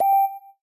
beginningSignal1.ogg